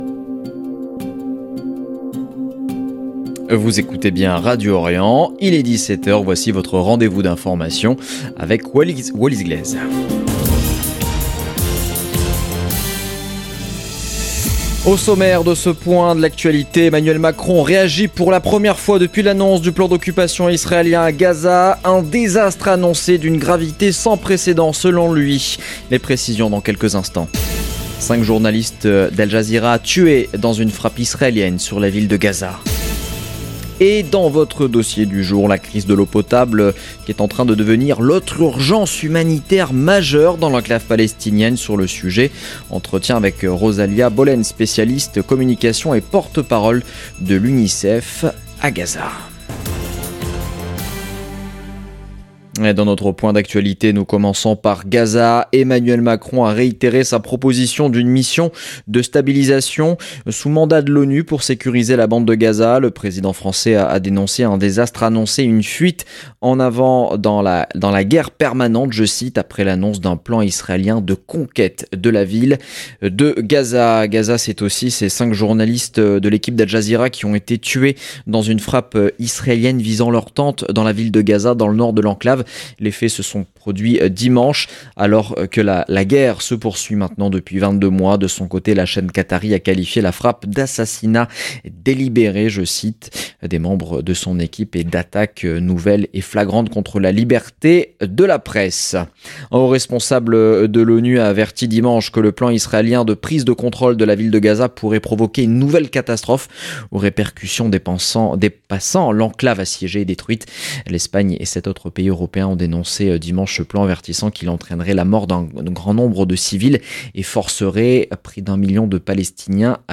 Magazine d'information de 17H du 11 août 2025